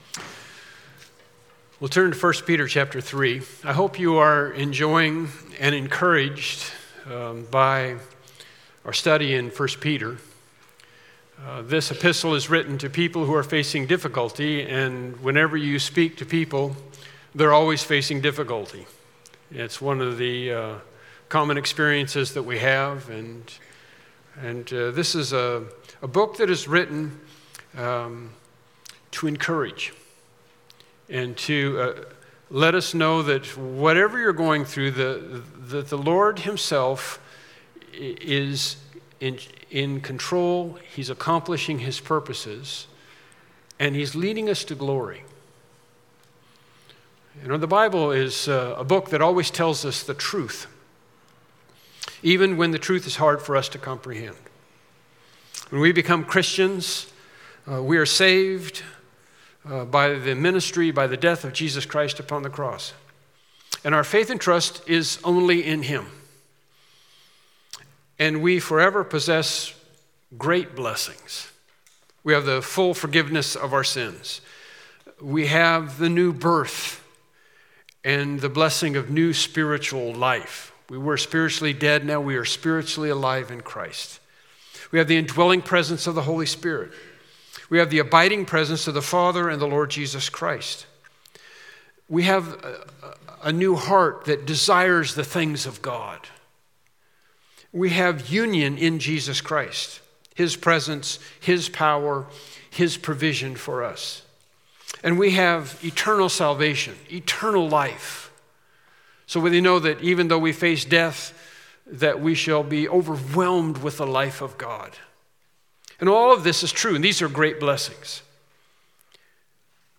1 Peter 3:17-20 Service Type: Morning Worship Service Topics